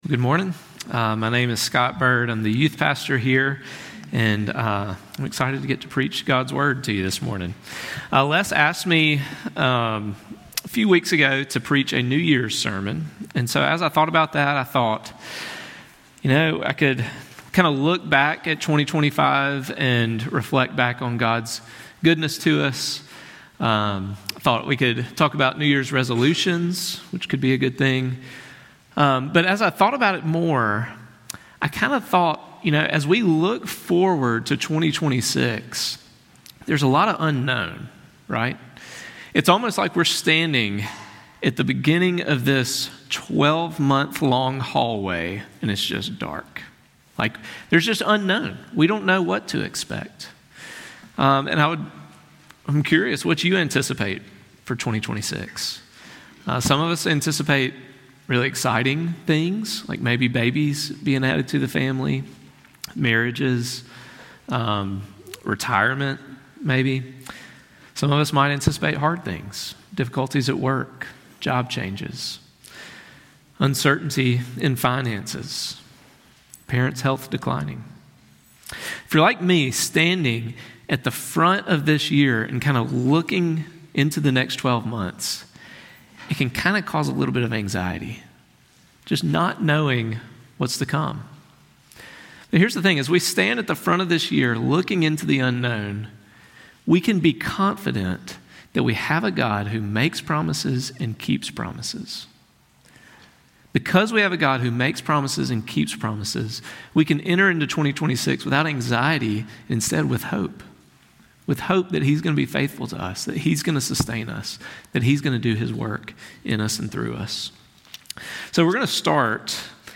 We can be confident that the same God who promised and delivered the land to His people has made promises to us and He is faithful to keep them. Sermon Points: God Makes Promises God's Promises Seem Dim God Is Faithful to His Promises